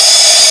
067 - HH-7O.wav